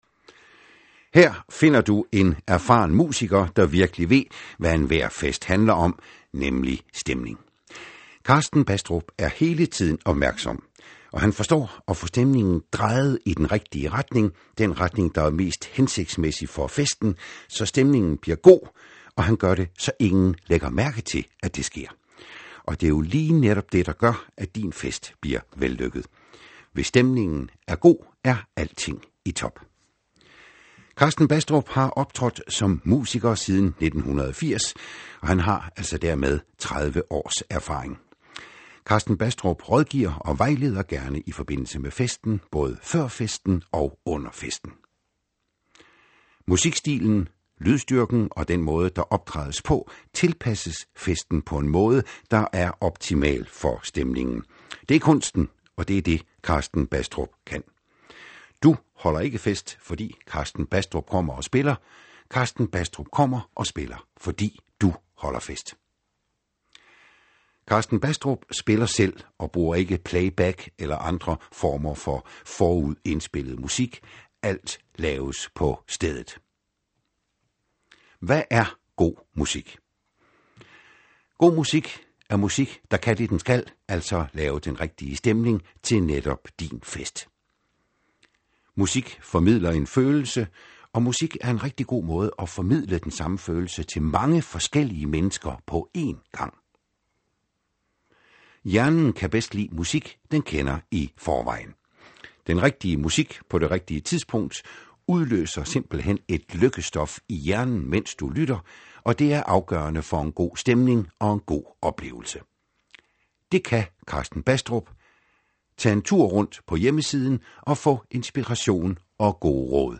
Kort
præsentation læst af Nis Boesdal